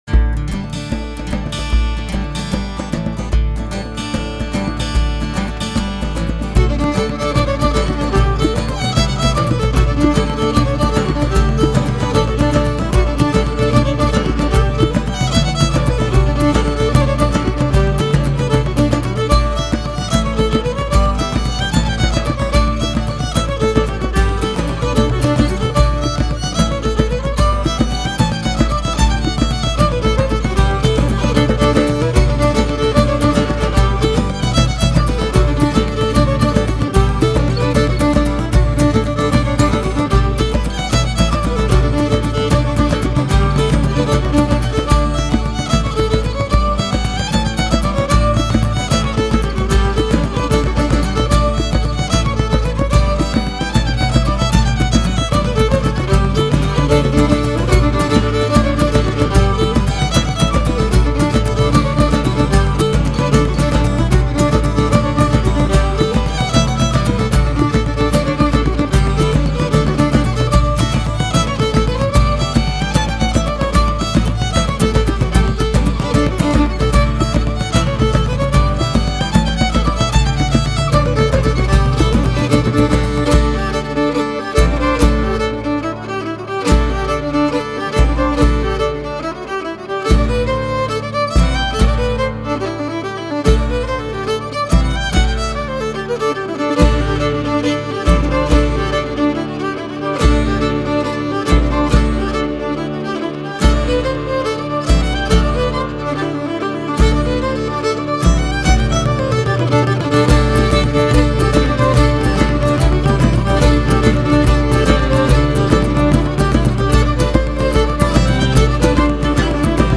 Кельтская